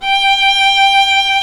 Index of /90_sSampleCDs/Roland L-CD702/VOL-1/STR_Viola Solo/STR_Vla1 % + dyn